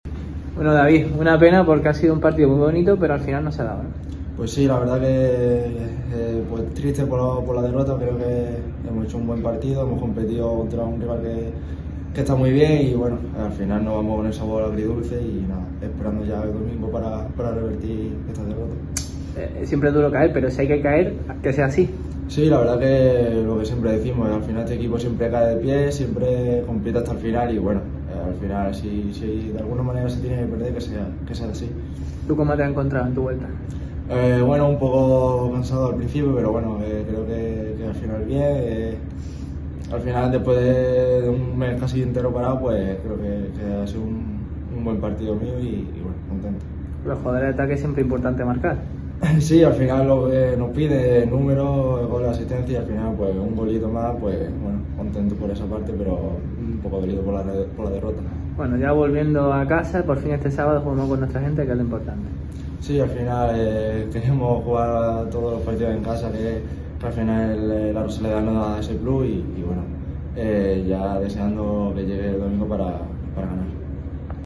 “Si se tiene que perder que sea así”, analizan los goleadores en zona mixta.